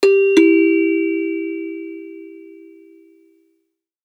notification-sound.mp3